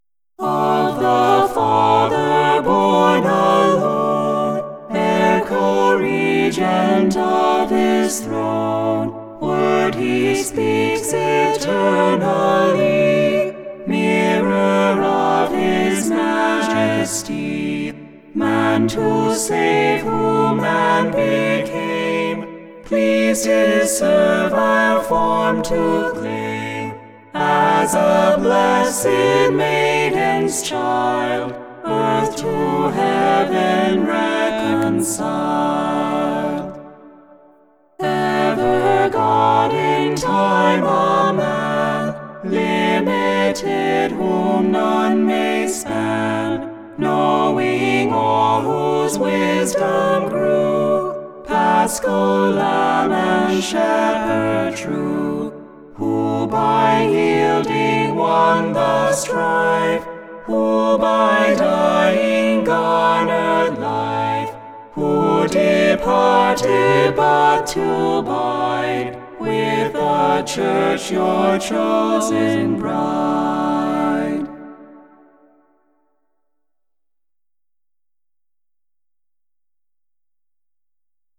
Can you hear the difference between a (poorly trained) male voice singing Alto and a (well trained) female voice?